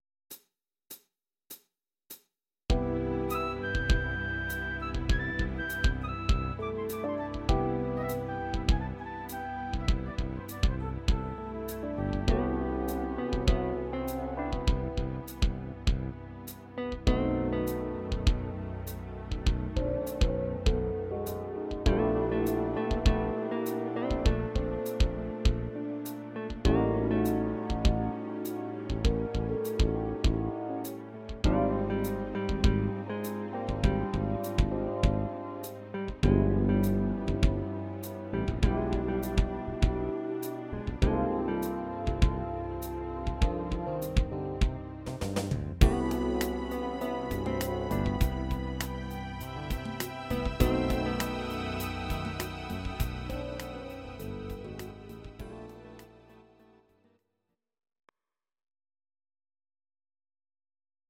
Audio Recordings based on Midi-files
Pop